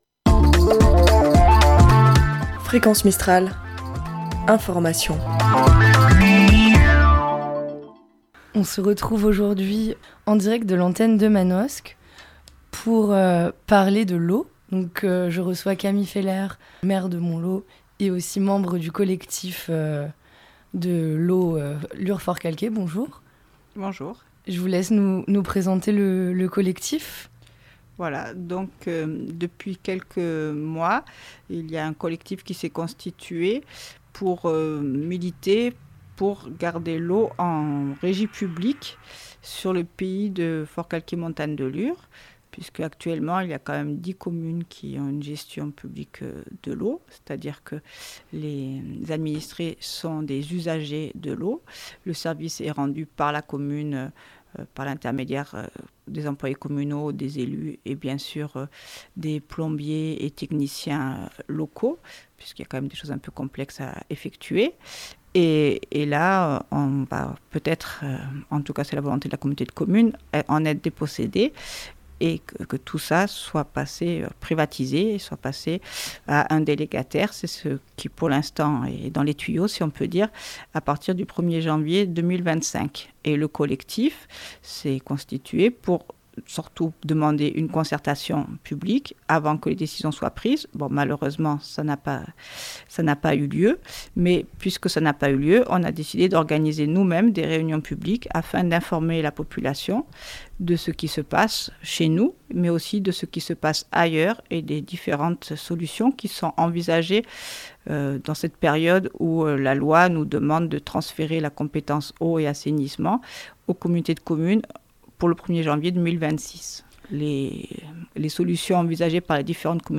Collectif Eau Publique Lure Forcalquier - Interview